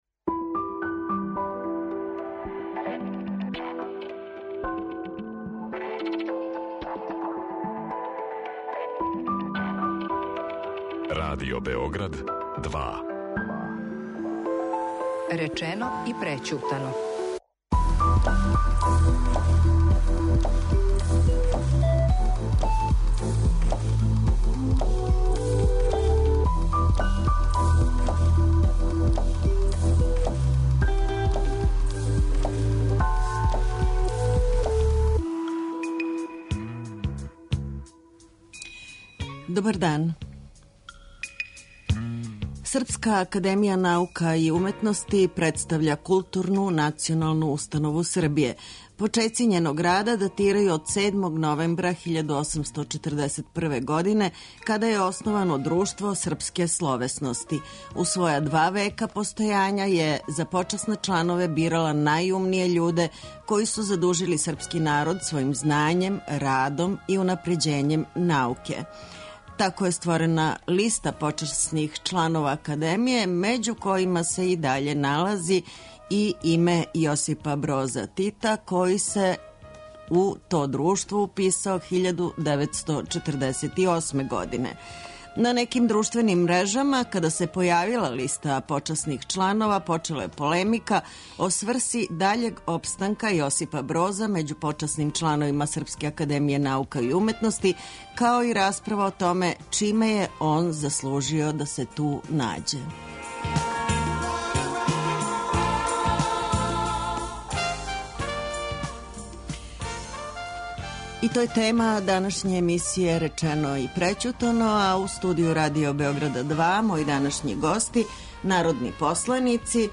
Говоре народни посланици Александар Чотрић и Јосип Јошка Броз.